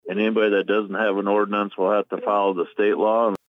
That was Sheriff Dean Kruger on the jurisdiction pertaining to those who live outside of the community and city limit areas.